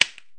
板子使用扬声器播放一个按键音的时候，偶然出现一声较正常声音响亮的声音，这种情况好像也不叫破音，只是比正常音量大。
btnSnd.wav